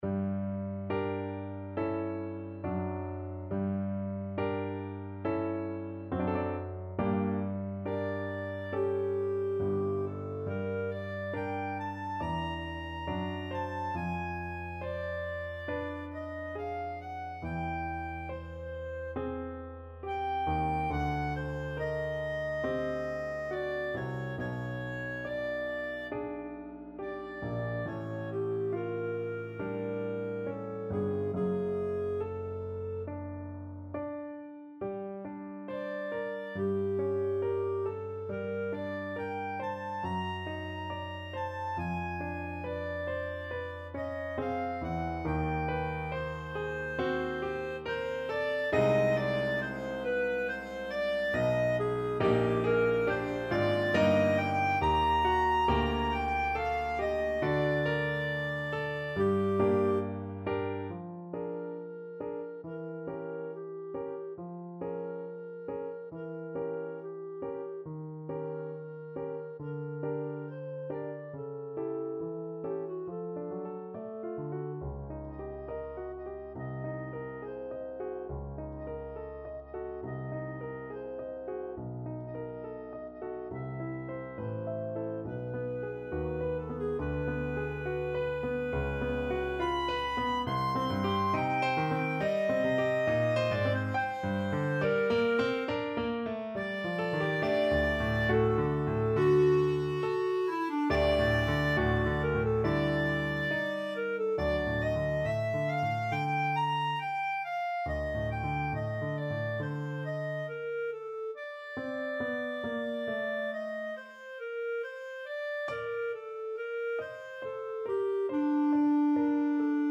4/4 (View more 4/4 Music)
~ = 69 Andante con duolo
Classical (View more Classical Clarinet Music)